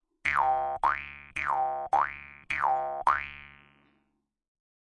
口腔竖琴第1卷 " 口腔竖琴5形变
描述：口琴（通常被称为“犹太人的竖琴”）调到C＃。 用RØDENT2A录制。
Tag: 竖琴 调整Mouthharp 共振峰 仪器 传统 jewsharp 共振峰 弗利